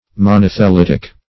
Meaning of monothelitic. monothelitic synonyms, pronunciation, spelling and more from Free Dictionary.